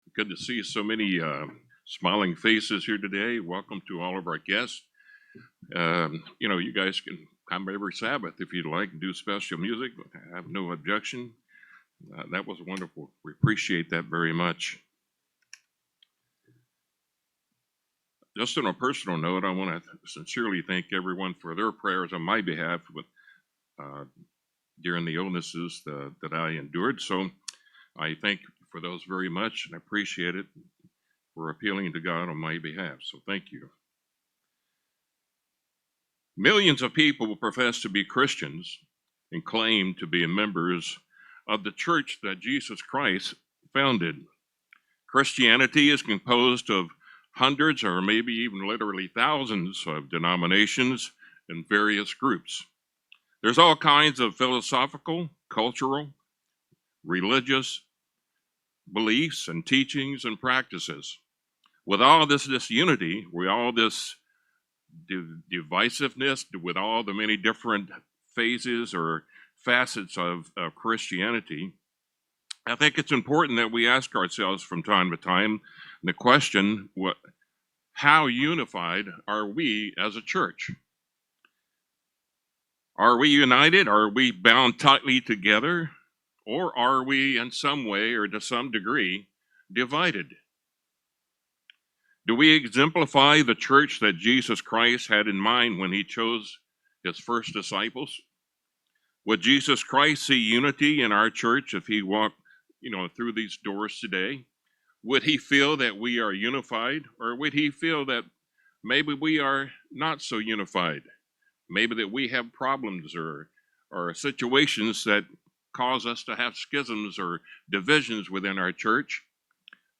Sermons
Given in Tampa, FL